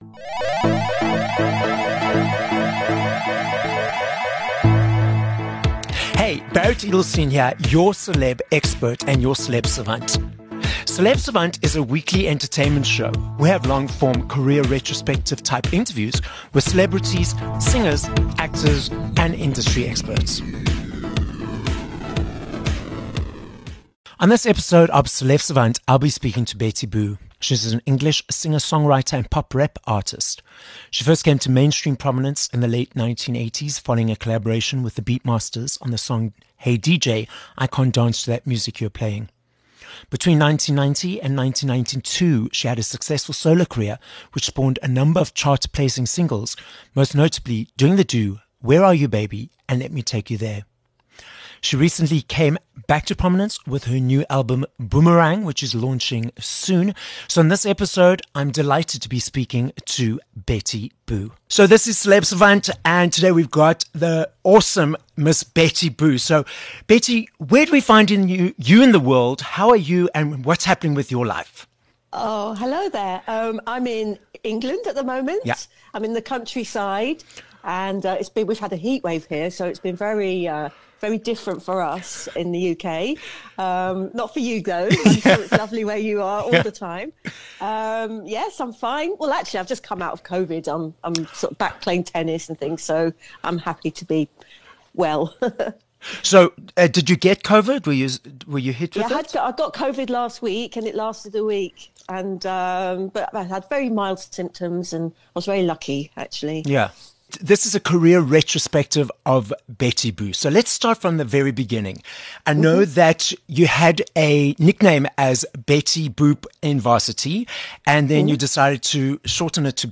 13 Aug Interview with Betty Boo